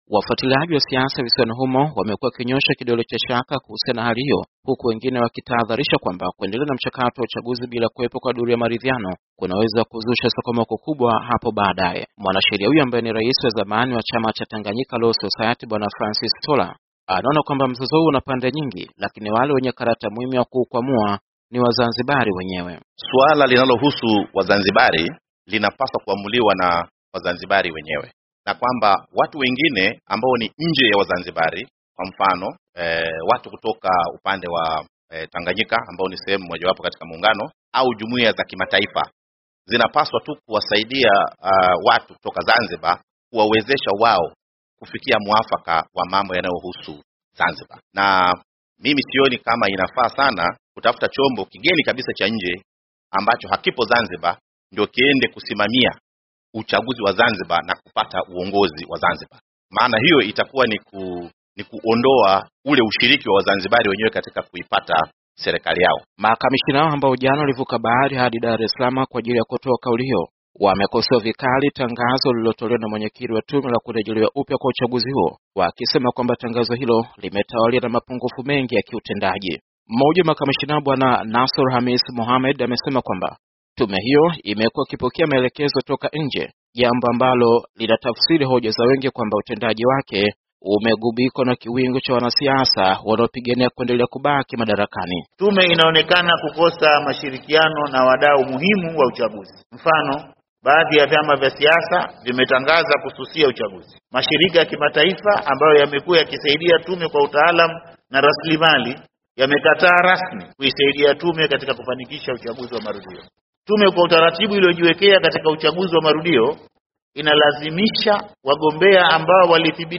Kutoka Dar es salaam